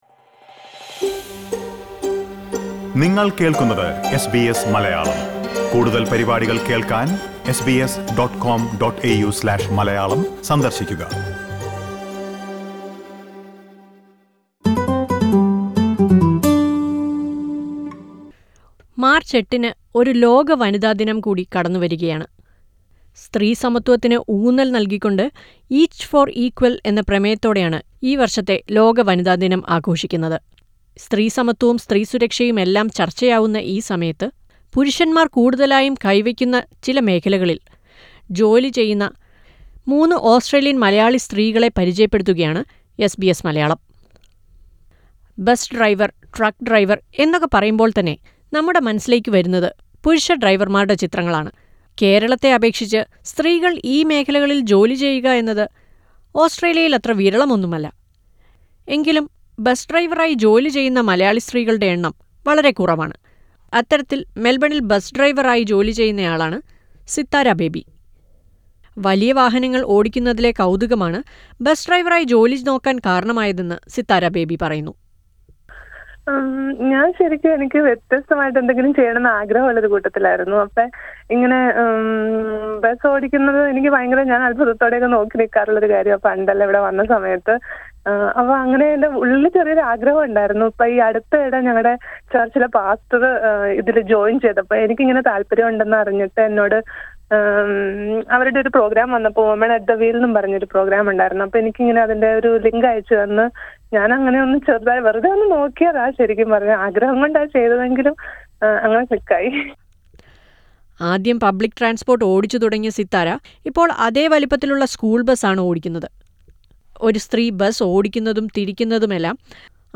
This Women's Day SBS Malayalam speak to a few inspiring Malayalee women who work in male dominated fields. Listen to a report on this.